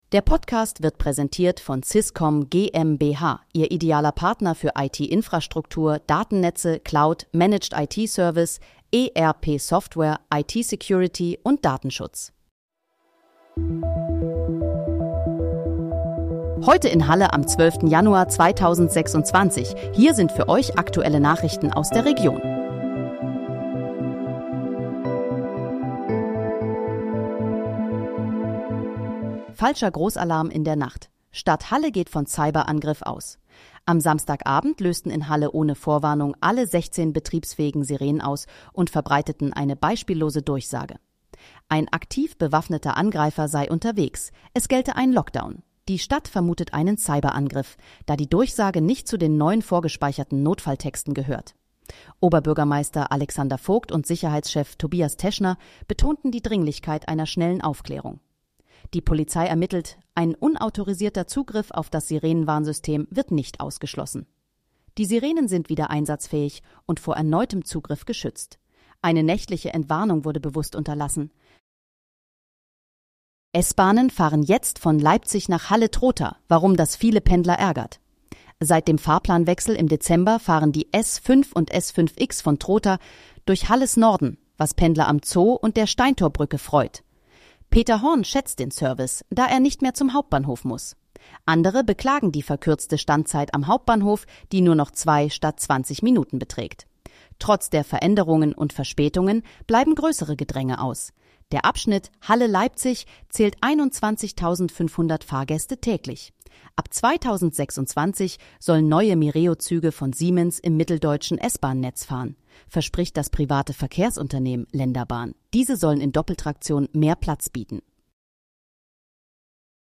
Heute in, Halle: Aktuelle Nachrichten vom 12.01.2026, erstellt mit KI-Unterstützung
Nachrichten